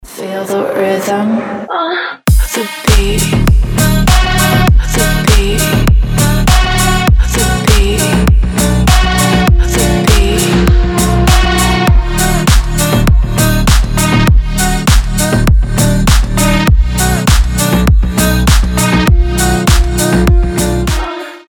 • Качество: 320, Stereo
deep house
атмосферные
басы
Midtempo
чувственные
Слегка неприличные звуки, но мотив суперский